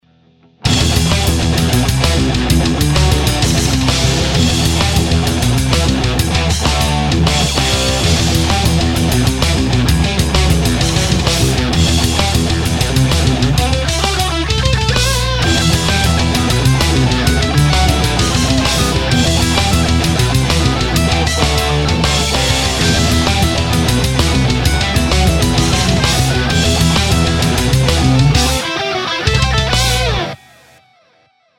������� Heavy Metal �� �����)��������� ������ � �����, ���� ����� �����������.[attach=1]P.S. ������� ����� ���� ����� (Live)Кор...